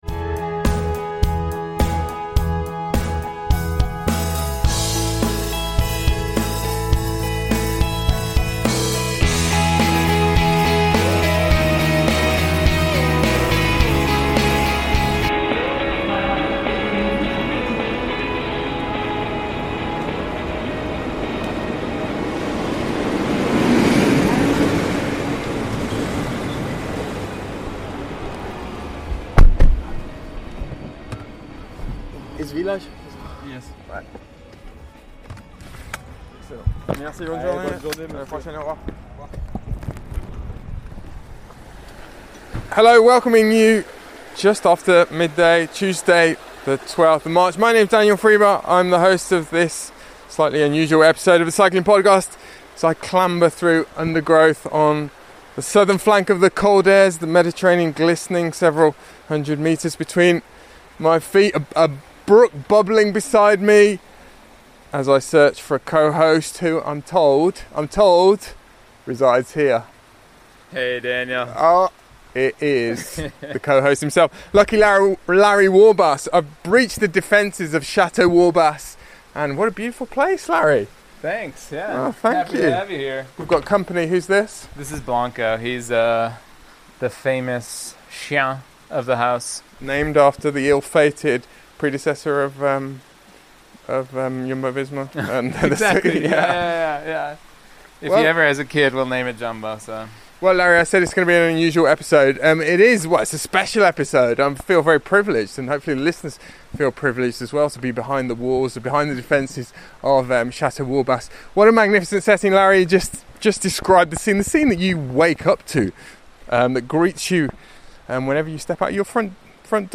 This week’s episode of The Cycling Podcast is a special dispatch from the Côte d’Azur, partly recorded at the home of our own Captain America - better known to us as Décathlton-AG2r La Mondiale’s ‘Motown Maestro’ Larry Warbasse - and featuring an exclusive interview with Visma...